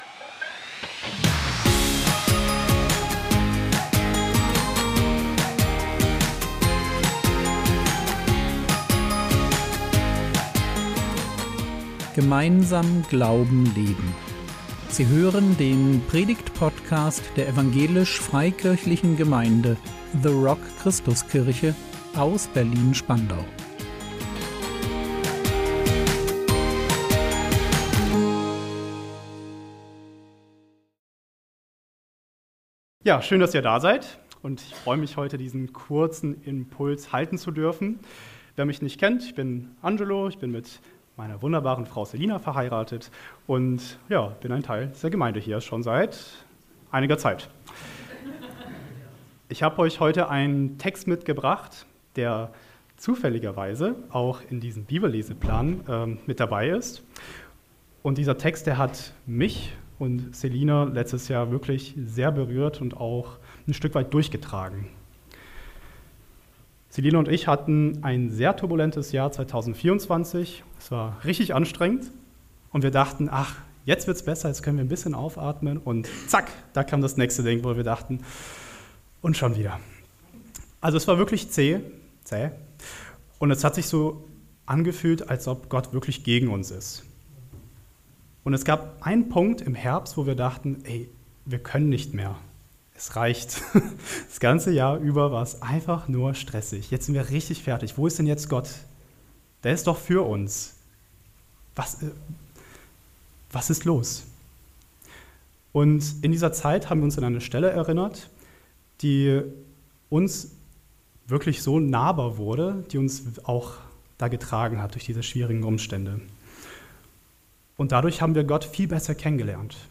Segen durch Schmerz – Jakobs Begegnung mit Gott | 09.02.2025 ~ Predigt Podcast der EFG The Rock Christuskirche Berlin Podcast